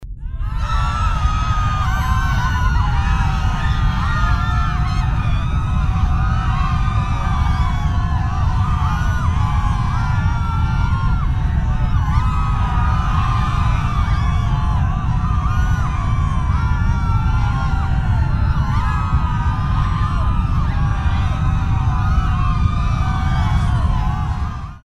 На этой странице собраны звуки землетрясений разной интенсивности: от глухих подземных толчков до разрушительных катаклизмов.
Крики людей во время землетрясения